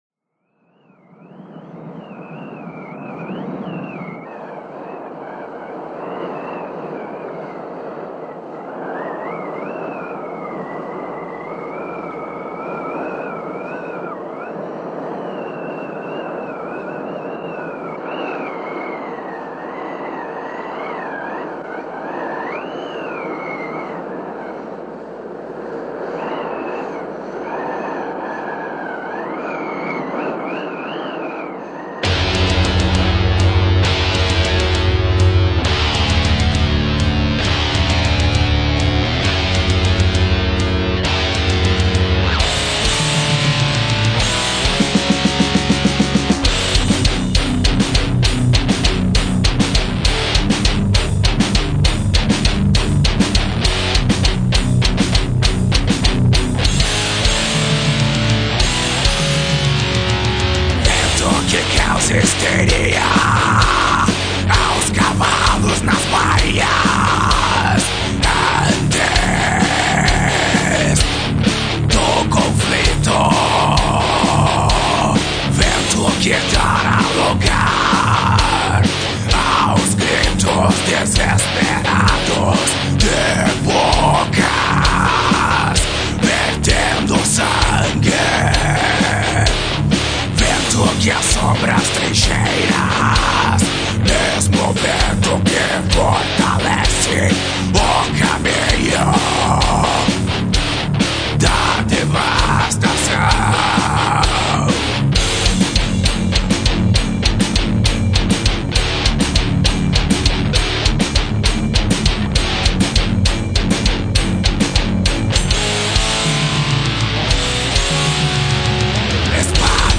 EstiloDeath Metal